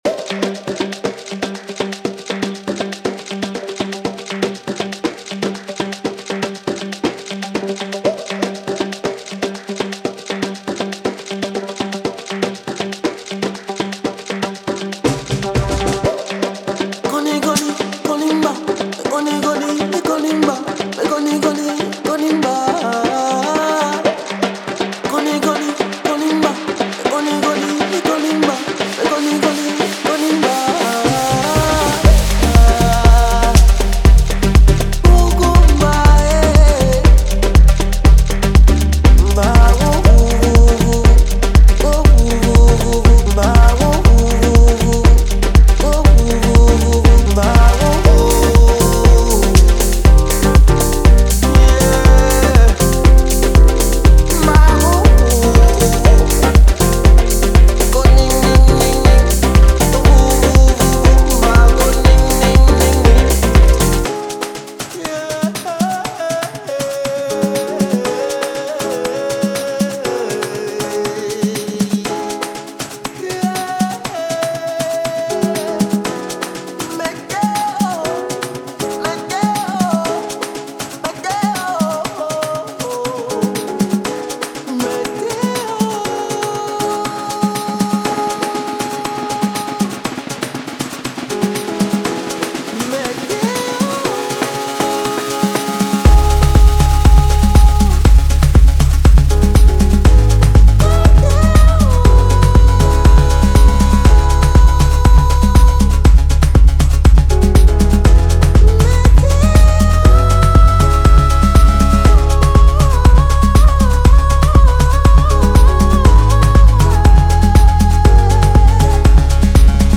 Clean, punchy samples to create your own unique grooves
Feel the vibe, Analog and Organic Melodic Loops